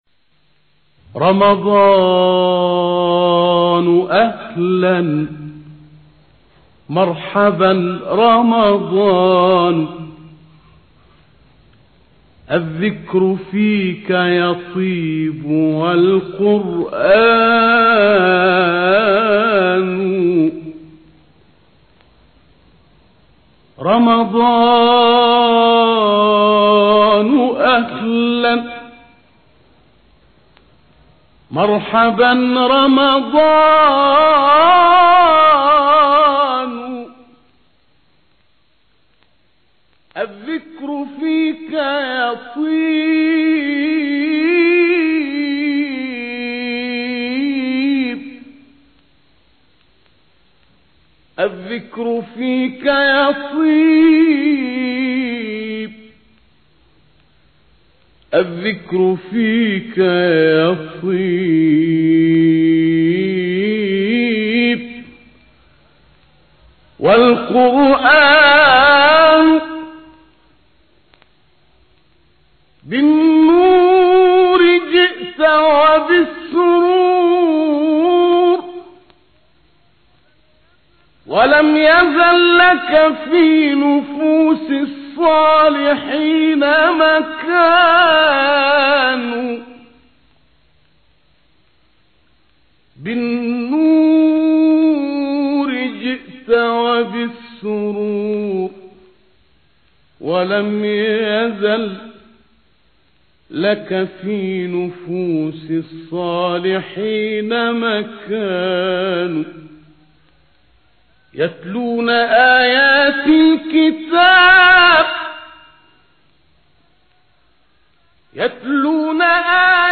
گروه شبکه اجتماعی: ابتهال کوتاه «رمضان اهلا» با صوت سیدمحمد نقشبندی به مناسبت فرارسیدن ماه مبارک رمضان ارائه می‌شود.
به گزارش خبرگزاری بین المللی قرآن(ایکنا) ابتهال «رمضان اهلا» با نوای مرحوم سيدمحمد النقشبندی، مبتهل برجسته مصری در کانال تلگرامی اکبرالقراء منتشر شد.
این ابتهال کوتاه به مناسبت حلول ماه مبارک رمضان اجرا شده است.